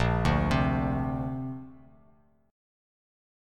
A#add9 chord